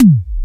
X E-DRUM 2.wav